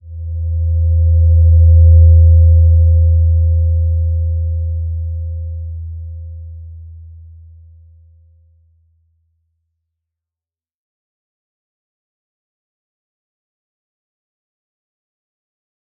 Slow-Distant-Chime-E2-mf.wav